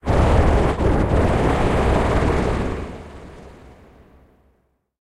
Cri de Shifours Gigamax Style Mille Poings dans Pokémon HOME.
Cri_0892_Gigamax_Mille_Poings_HOME.ogg